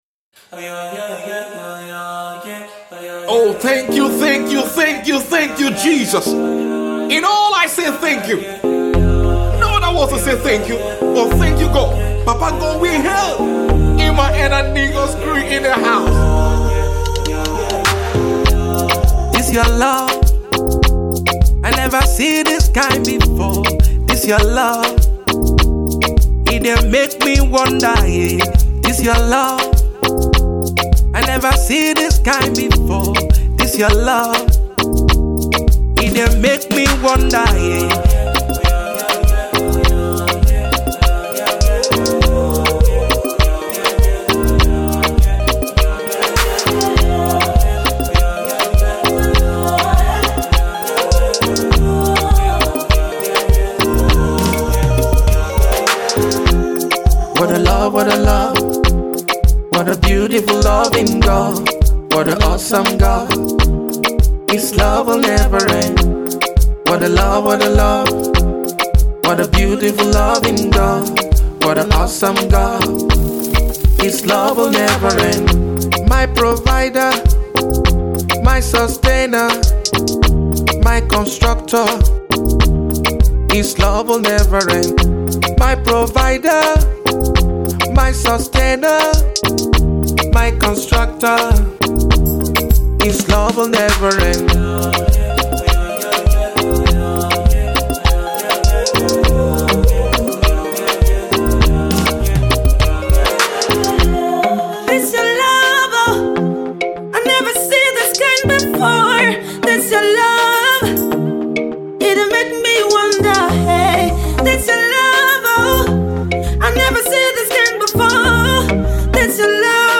Indigenous Nigerian Gospel artiste